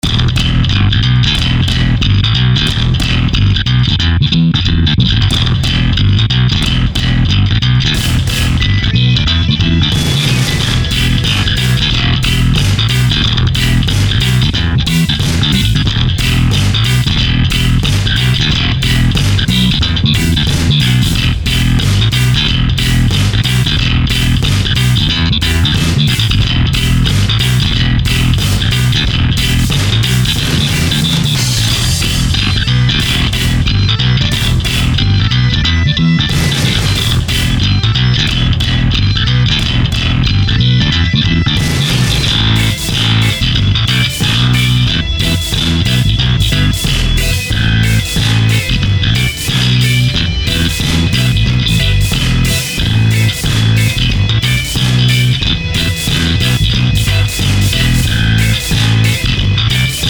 • Качество: 256, Stereo
гитара
без слов
Bass
рок